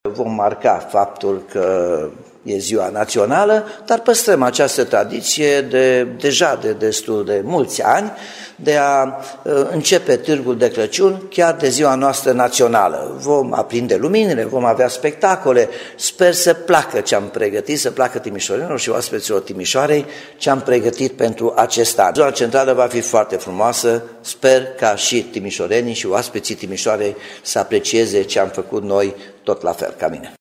Primarul Nicolae Robu spune că zona centrală va arăta spectaculos în acest an.